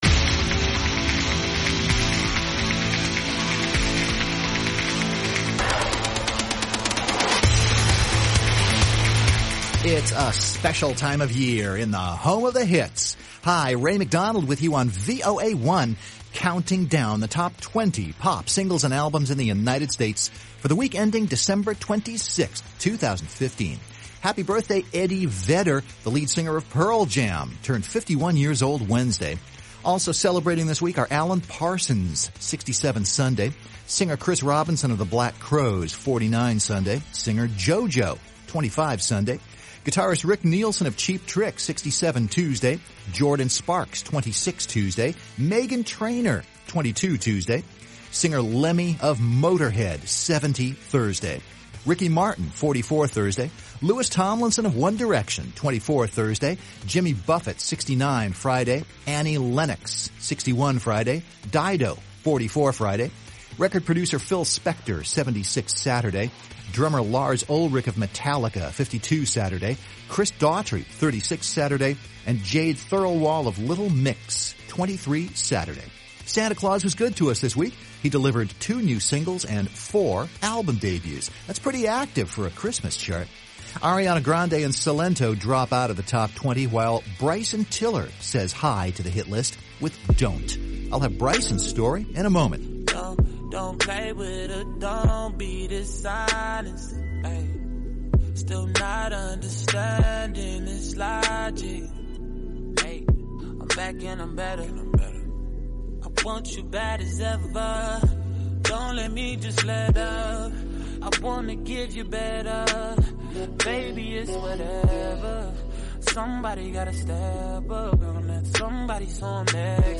countdown of the hottest pop, rock, and R&B singles and albums in the United States, as determined by the editors at Billboard magazine.